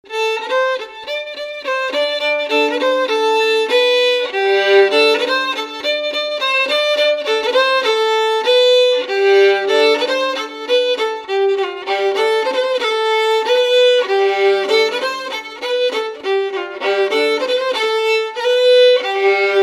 Résumé instrumental
branle : avant-deux
Enquête Arexcpo en Vendée
Pièce musicale inédite